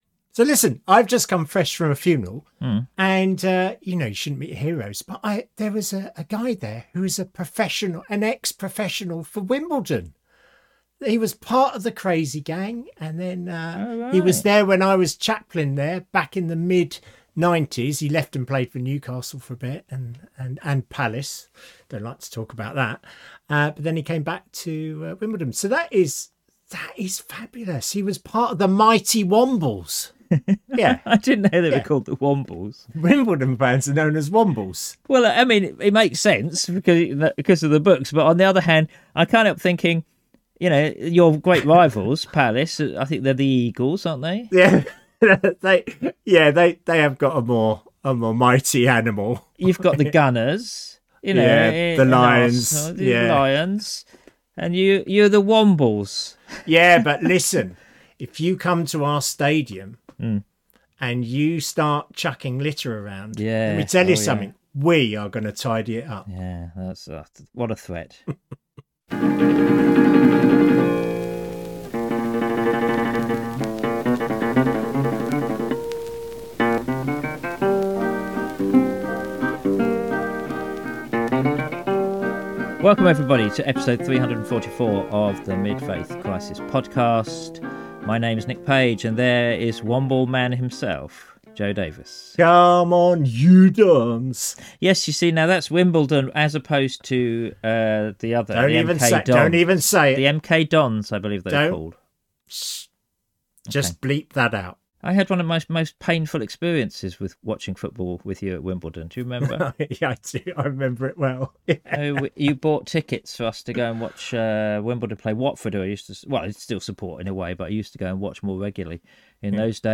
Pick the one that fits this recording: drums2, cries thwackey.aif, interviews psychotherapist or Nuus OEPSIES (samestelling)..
interviews psychotherapist